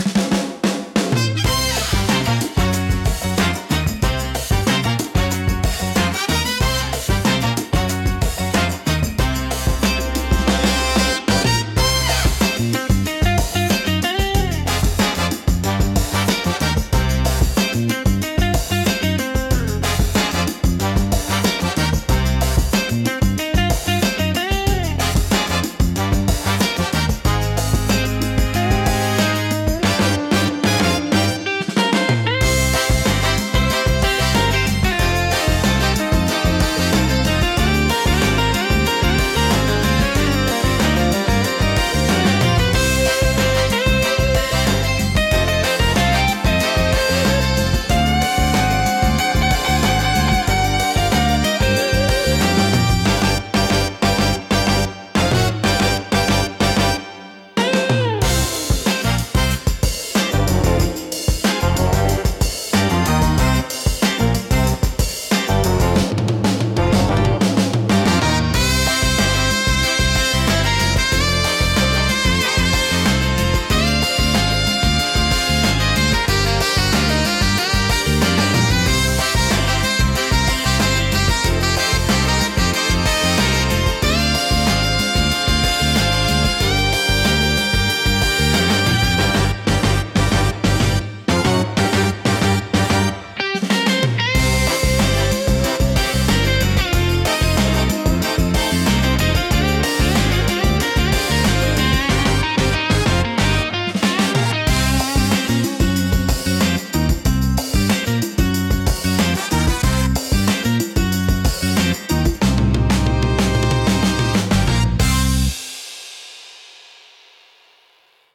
不穏さとエネルギーが同居し、聴く人の集中力を高めつつドキドキ感を作り出します。